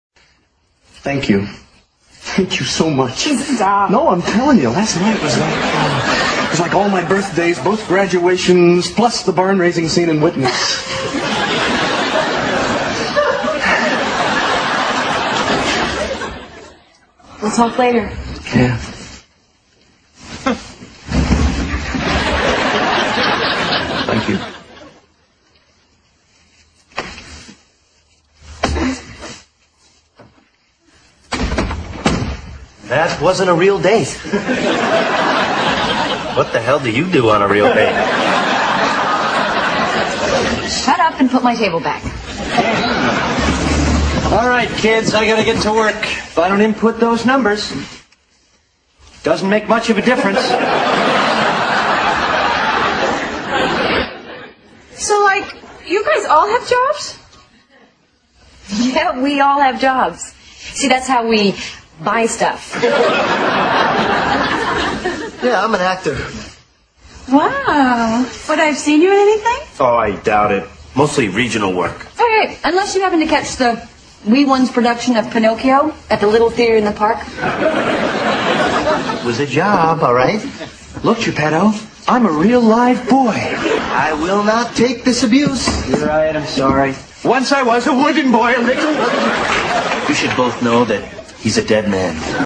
在线英语听力室老友记精校版第1季 第7期:莫妮卡的新室友(7)的听力文件下载, 《老友记精校版》是美国乃至全世界最受欢迎的情景喜剧，一共拍摄了10季，以其幽默的对白和与现实生活的贴近吸引了无数的观众，精校版栏目搭配高音质音频与同步双语字幕，是练习提升英语听力水平，积累英语知识的好帮手。